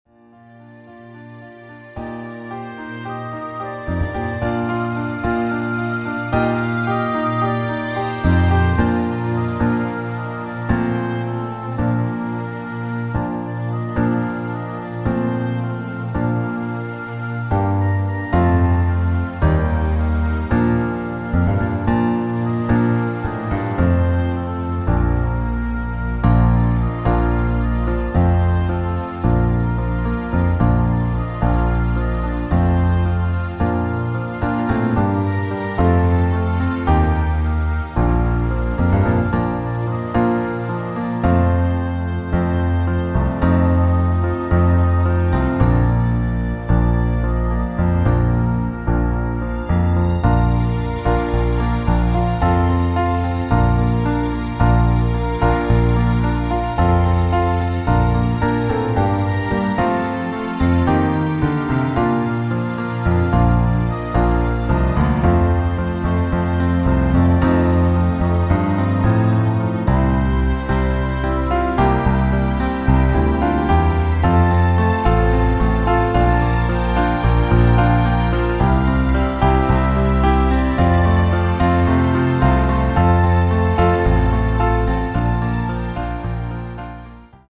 • Tonart: Bb Dur, G Dur (Originaltonart)
• Art: Klavierversion mit Streichern
• Das Instrumental beinhaltet NICHT die Leadstimme